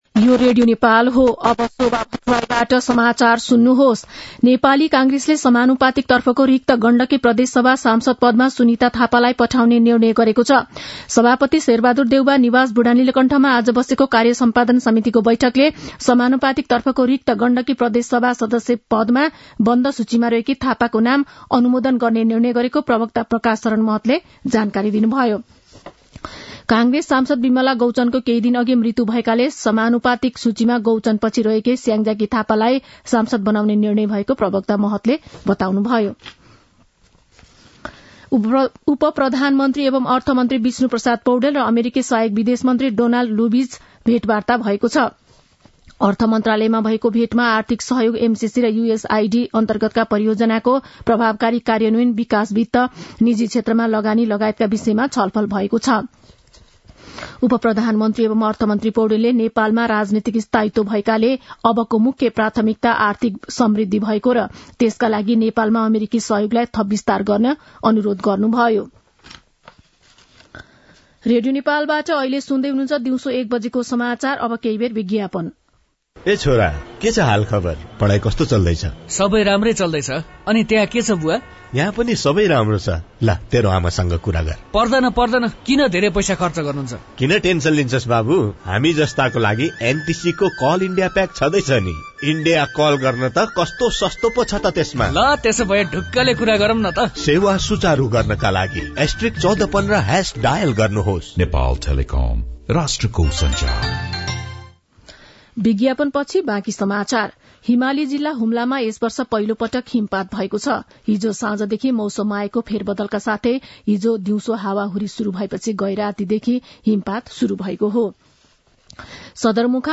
दिउँसो १ बजेको नेपाली समाचार : २५ मंसिर , २०८१
1-pm-nepali-news-1-7.mp3